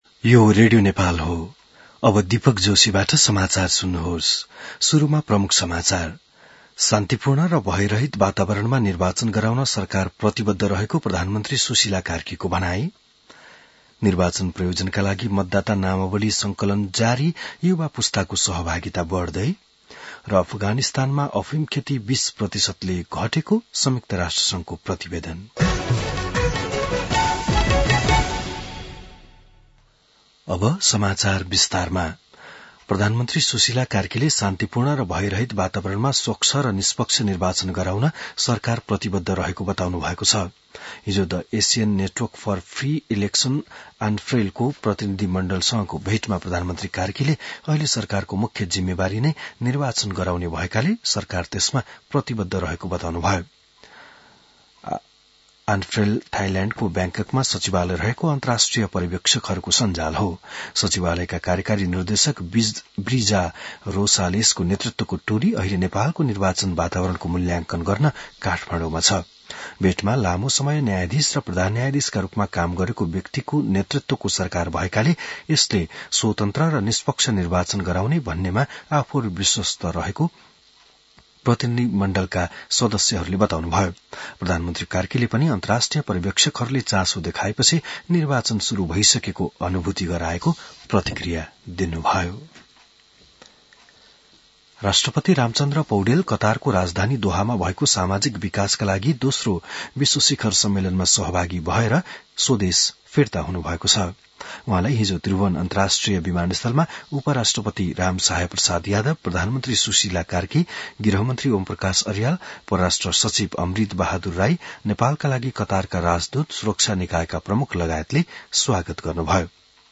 बिहान ९ बजेको नेपाली समाचार : २१ कार्तिक , २०८२